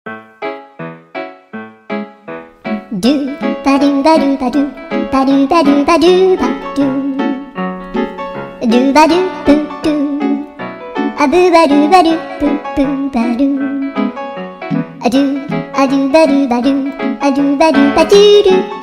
04 funny song Meme Sound Effect